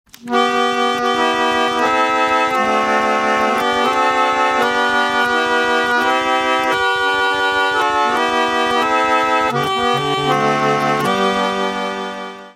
Gem Roller Organ
Una manivela se encargaba de hacer girar un cilindro con púas cuya acción levantaba las llaves por donde salía el aire, que previamente había pasado por una lengüetas.
Pista de audio: Grabación realizada con el instrumento original expuesto en la Fundación Joaquín Díaz de Urueña.
roller.mp3